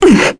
Lorraine-Vox_Damage_kr_01.wav